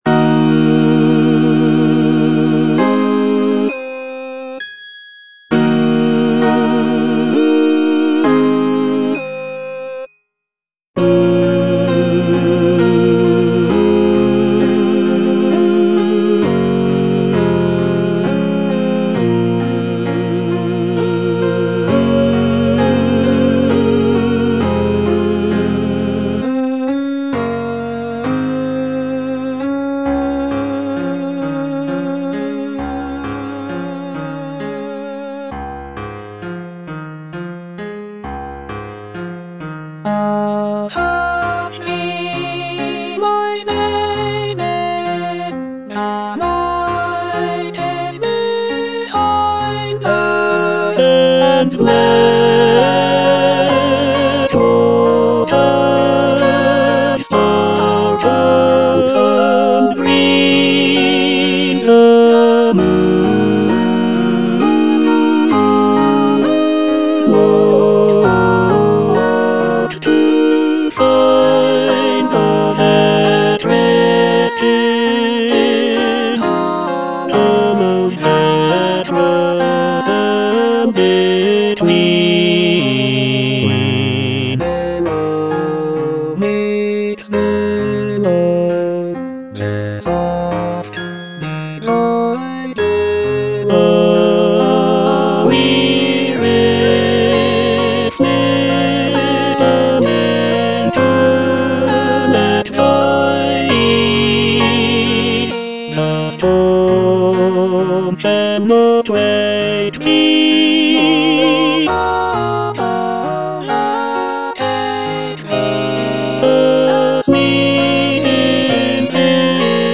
Tenor (Emphasised voice and other voices)